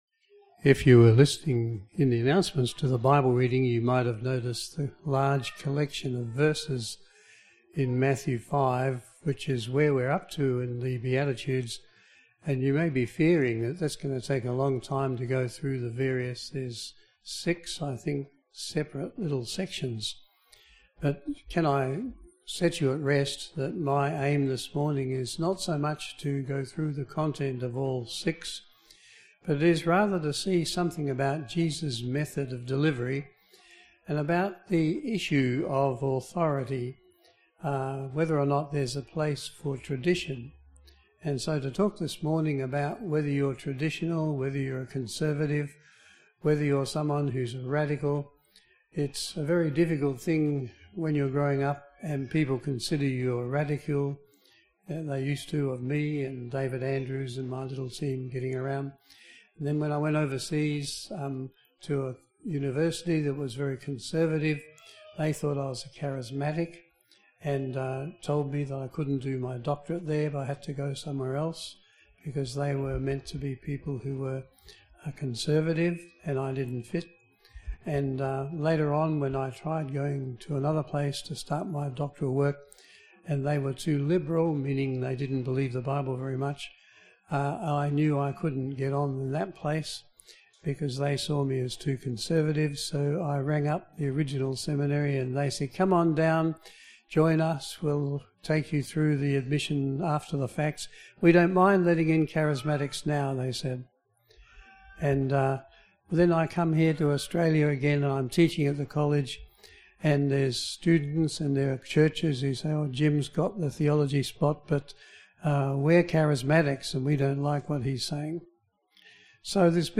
Service Type: AM Service